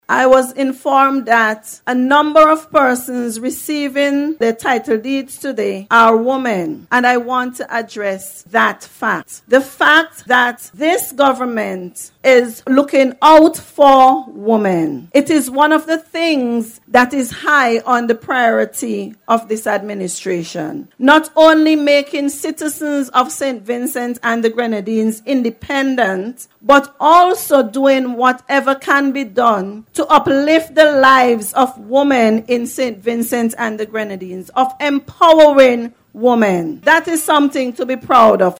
She made this statement during the ceremony which was held on Tuesday to distribute title deeds to more than eighty residents of North Windward.